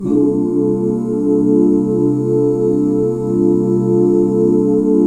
CSUS13 OOO.wav